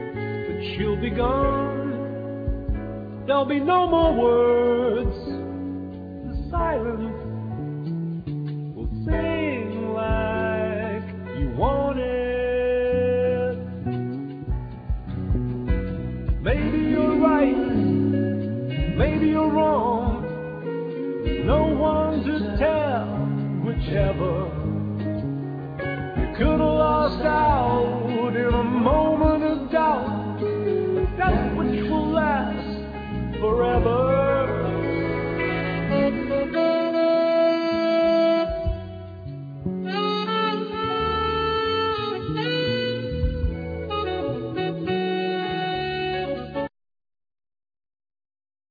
Vocal,Piano,Samples
Guitar,Banjo,Trombone
Drums
Double bass
Tenor saxophone
Accordion
Violin